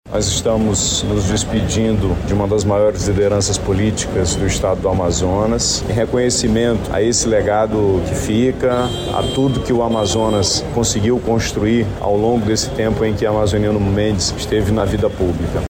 Amazonino Mendes é velado no Teatro Amazonas com honras de chefe de Estado
O governador do Estado, Wilson Lima, destacou o legado deixado pelo político.